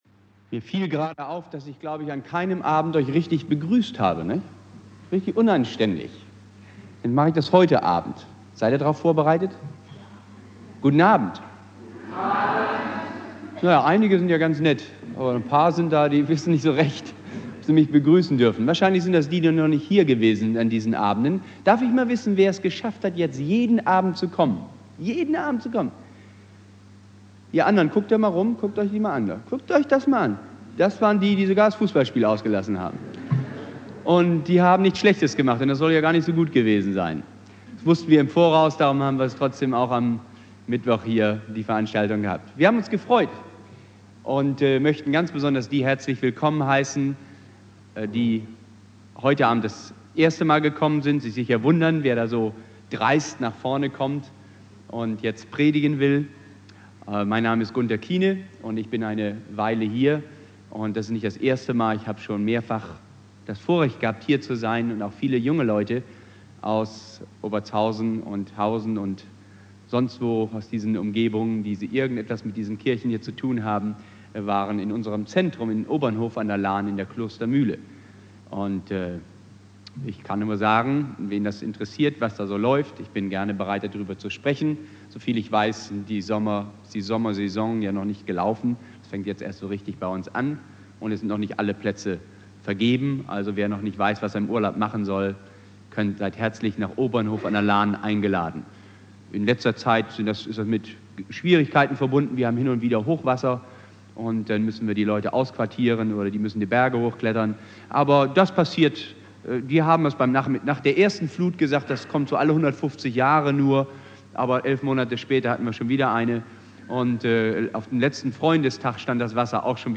Predigt
"Freude statt Sorgen" - vierter Abend der Evangelisation